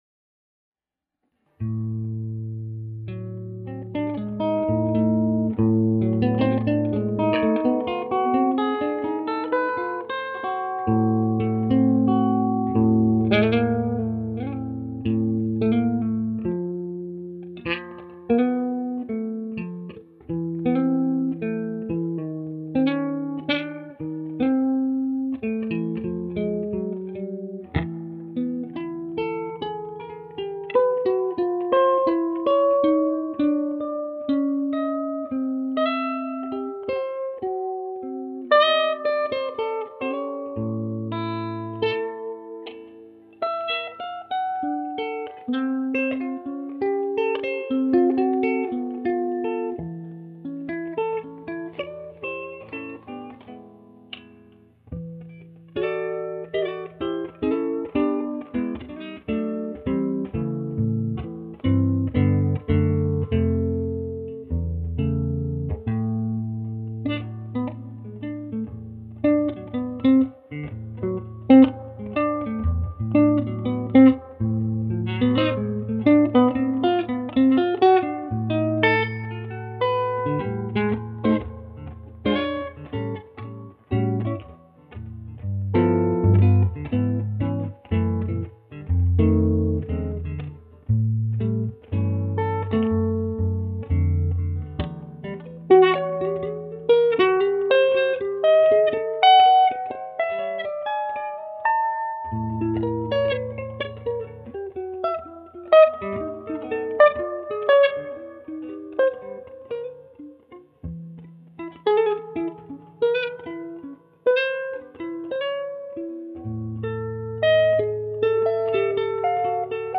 solo improvisation tracks